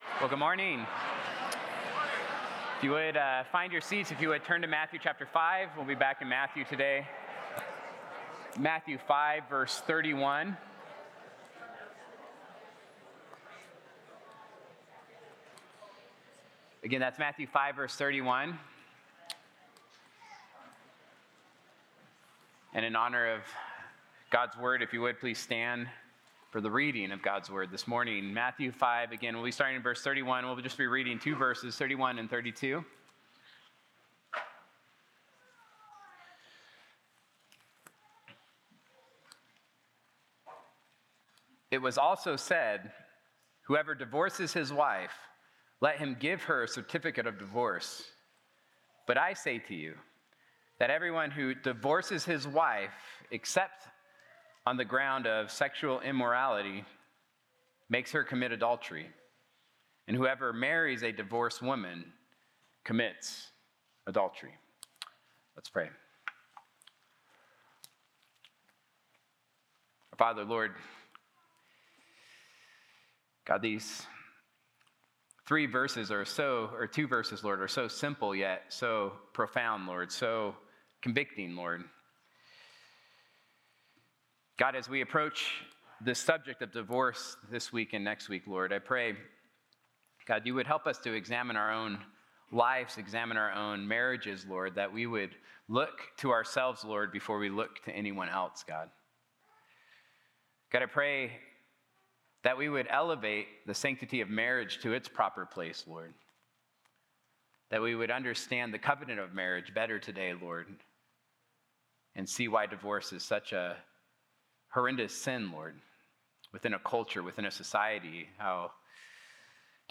Sunday-Sermon-October-5-2025.mp3